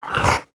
khanat-sounds-sources/sound_library/animals/monsters/mnstr8.wav at f42778c8e2eadc6cdd107af5da90a2cc54fada4c